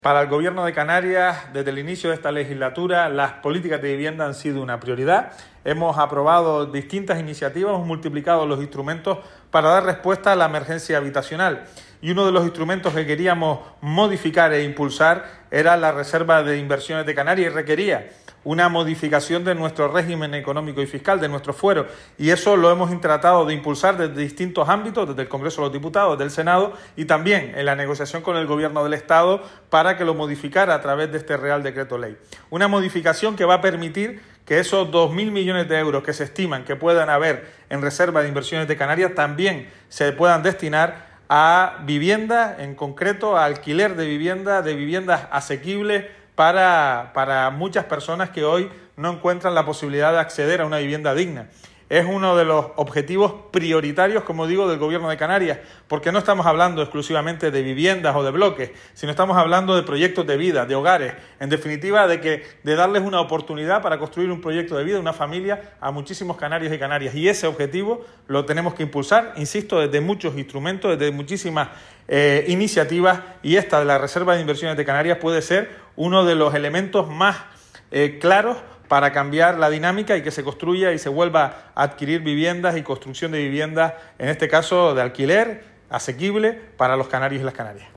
Entrevistas y declaraciones en Fuerteventura Digital El consejero de Vivienda de Canarias, Pablo Rodríguez, sobre la ampliación de la RIC para fomentar el alquiler asequible) Dec 24 2024 | 00:01:27 1x 00:00 / 00:01:27 Subscribe Share